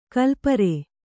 ♪ kalpare